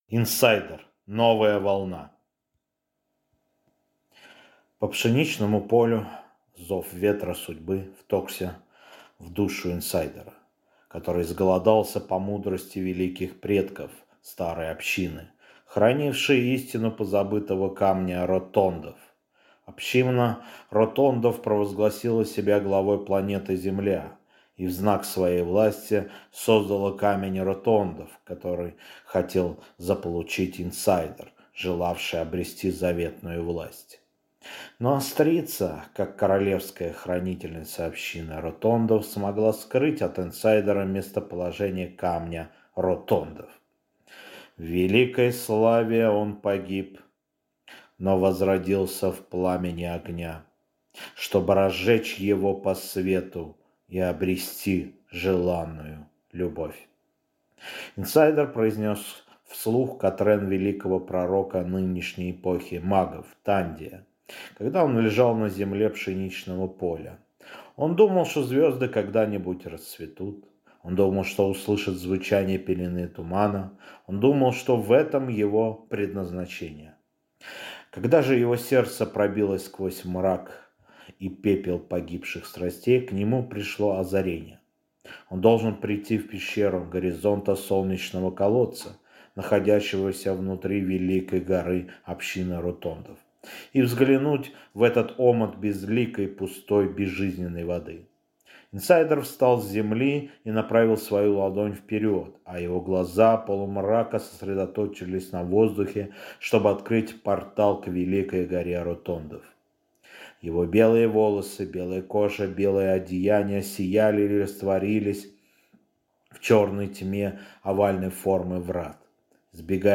Аудиокнига Инсайдер. Новая волна | Библиотека аудиокниг